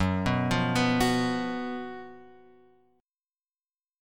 F# Major 7th Suspended 4th